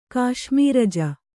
♪ kāśmīraja